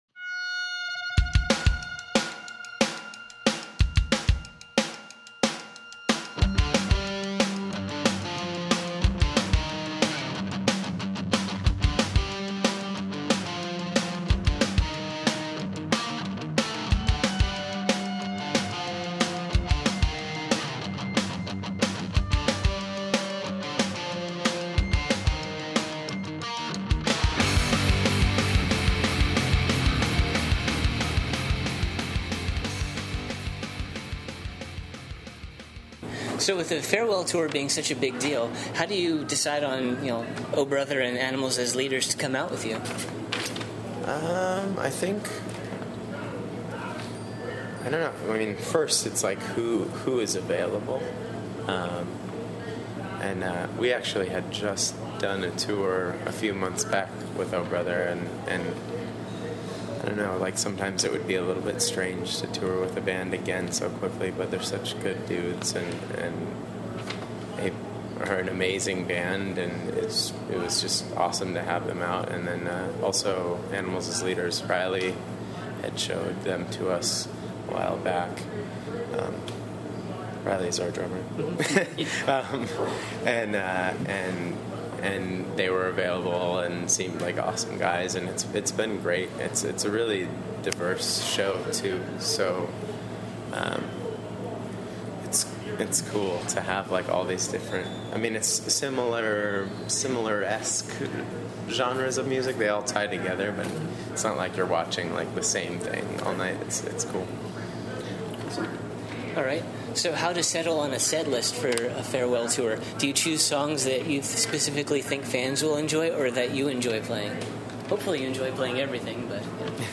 Exclusive: Thrice Interview
During Thrice‘s Lancaster stop of their Farewell Tour, we caught up with Teppei Teranishi & Eddie Breckenridge at the Chameleon Club. We talked about the band growing up and going on indefinite hiatus, the writing of Major/ Minor, their continued devotion to donating to charity, being self-sufficient on self-producing their last few records, upcoming side-projects (non-musical) and their favorite tour memory from their long musical career.
17-interview-thrice.mp3